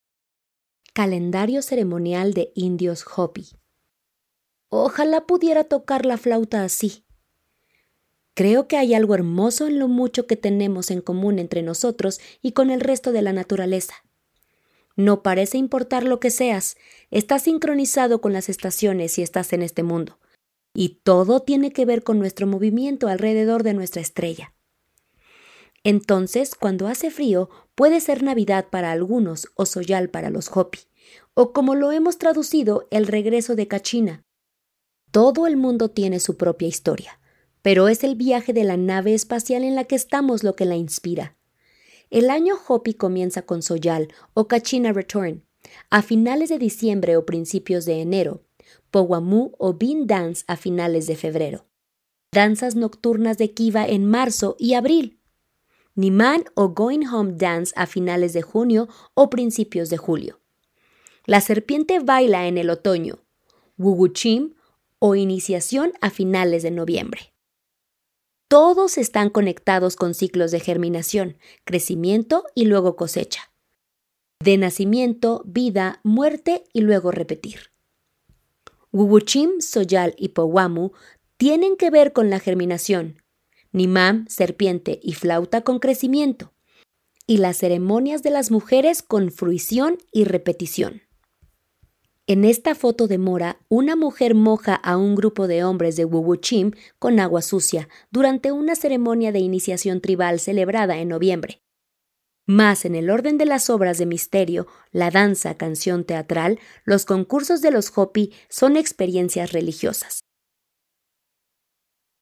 Ojalá pudiera tocar la flauta así. Wish I could play the flute like this.